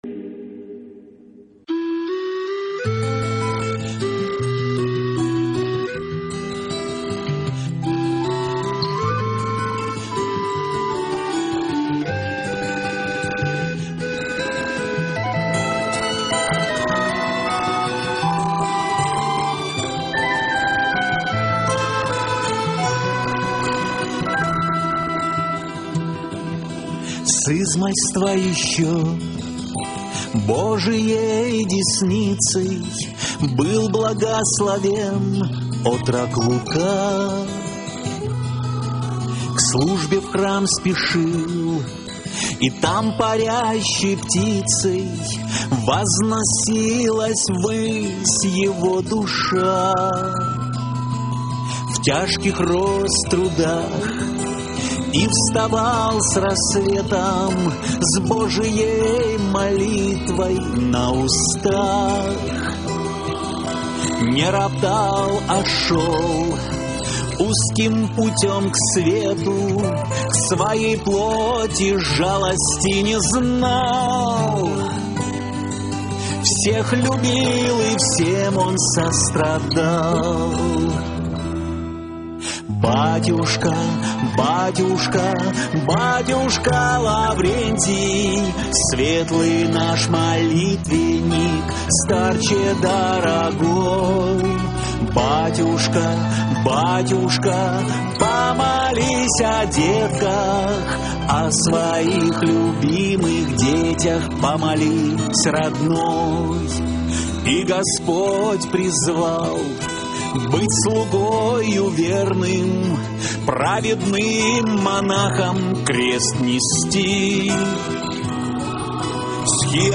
песню